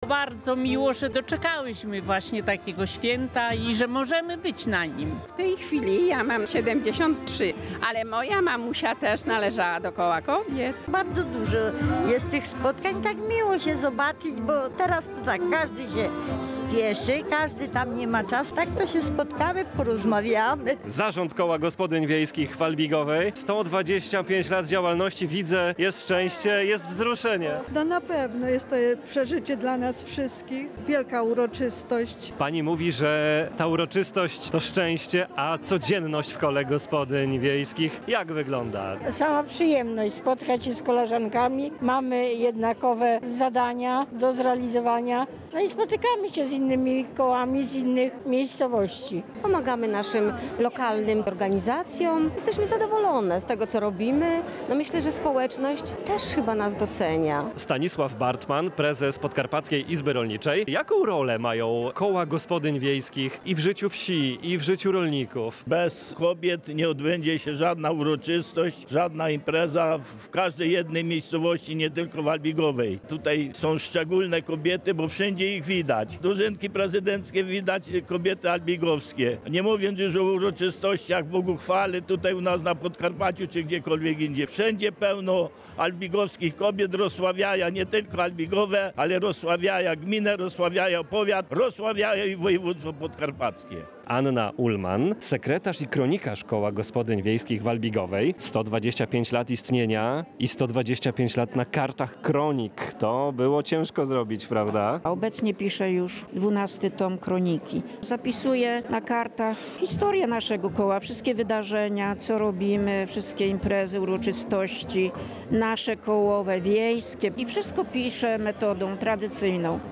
W sobotę 10 maja w Ośrodku Kultury w Albigowej odbyła się uroczysta gala.